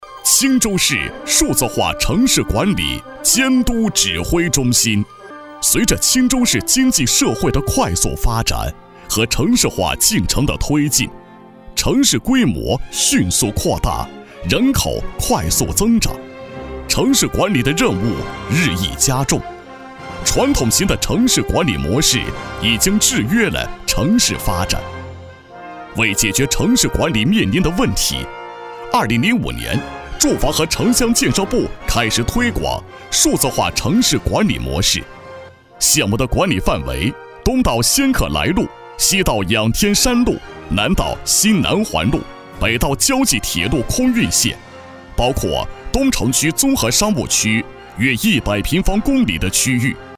大气震撼 企业专题,人物专题,医疗专题,学校专题,产品解说,警示教育,规划总结配音
大气豪迈男音，激情厚重，年轻活力。